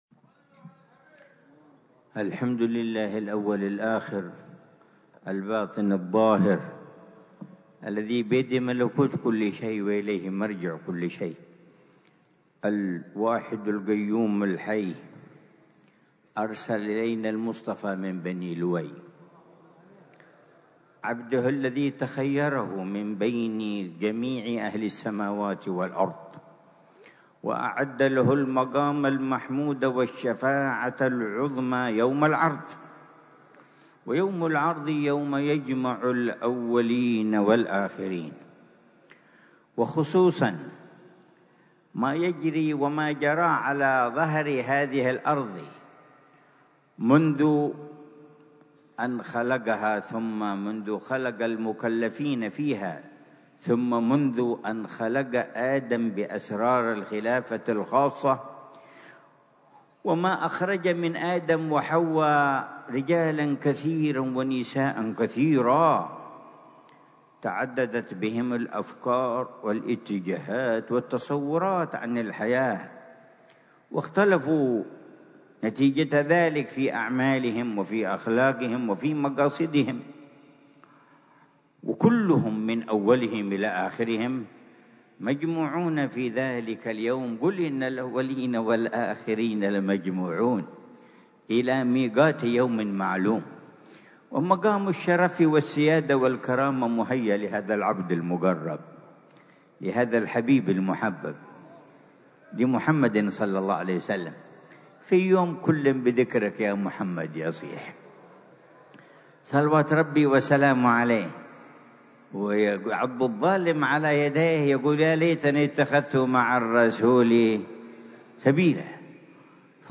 محاضرة العلامة الجبيب عمر بن محمد بن حفيظ ضمن سلسلة إرشادات السلوك، في دار المصطفى بتريم، ليلة الجمعة 20 شوال 1446هـ بعنوان: